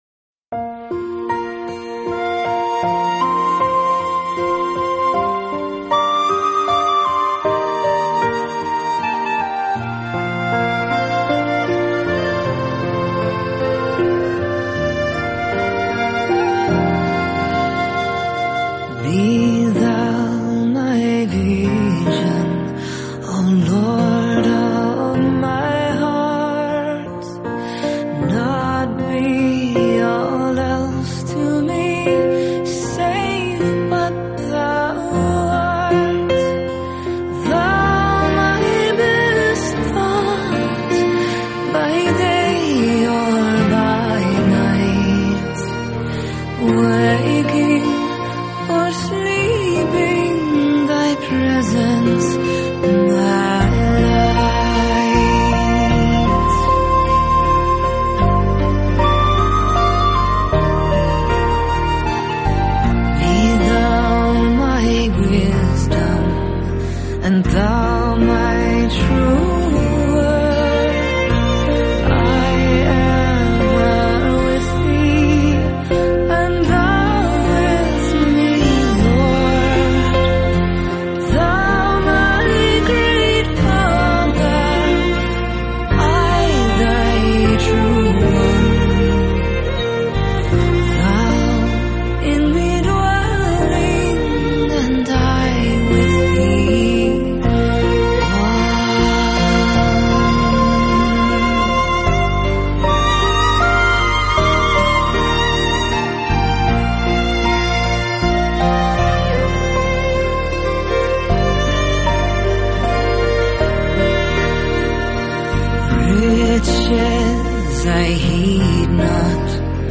单从凯尔特音乐的角度来讲，这张Vocal还是很好地把凯尔特元素融合进了
现代的演唱与配器方式中，可听度还是不算低的。